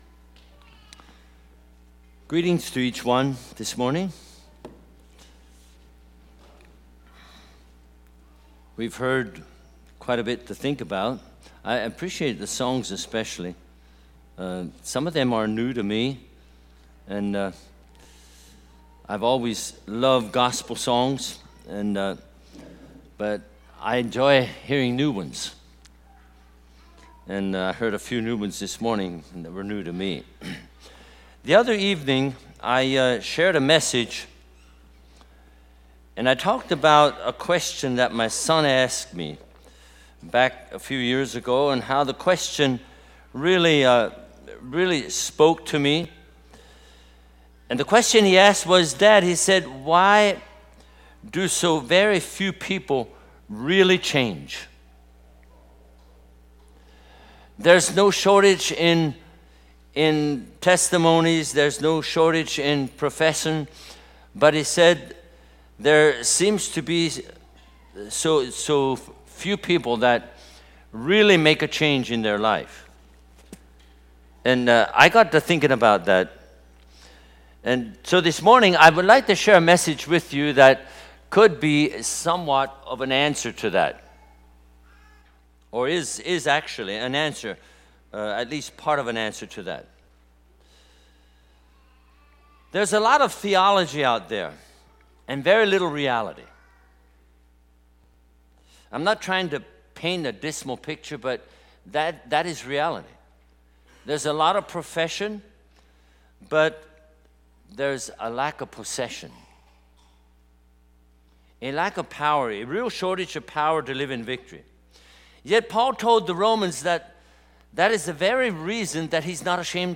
Bible Teaching Service Type: Sunday Morning